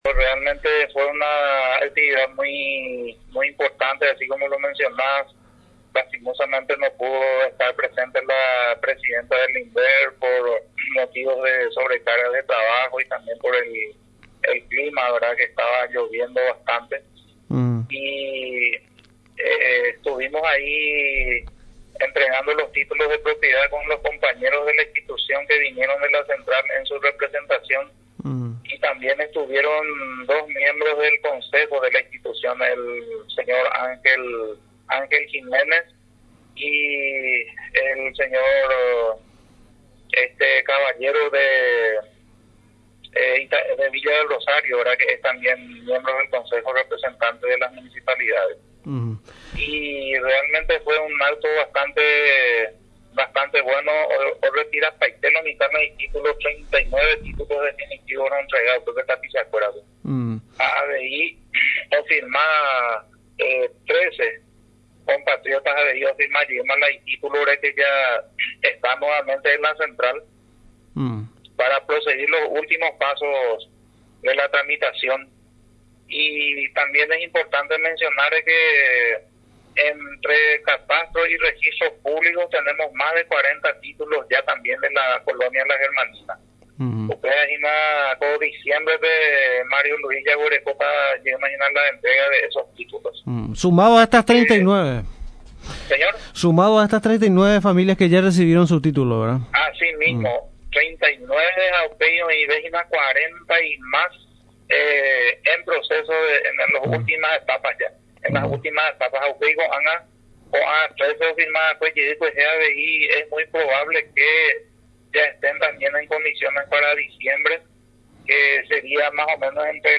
El director regional del ente rural en San Pedro en Radio Nacional San Pedro, resaltó la importancia de la exitosa actividad que fue aprovechada para firmar títulos para otros 13 colonos, quienes encaminan la regularización de la tenencia de sus lotes, mediante los trámites de finiquito de documentos dentro del proceso de titulación.